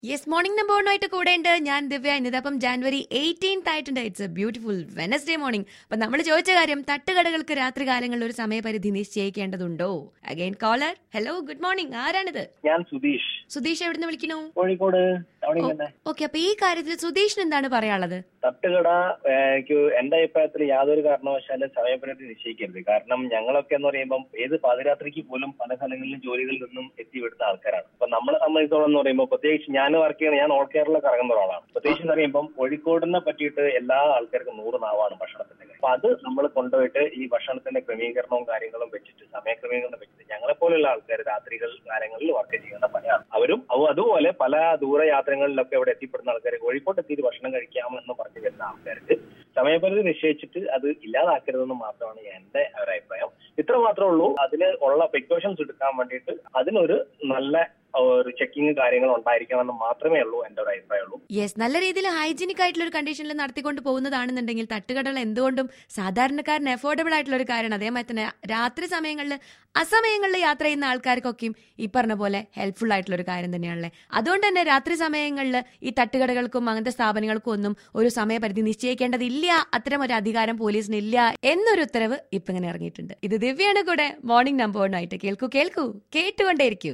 CALLER ON തട്ടുകടകൾക്കു രാത്രികാലങ്ങളിൽ സമയ പരിധി ആവശ്യമുണ്ടോ ??!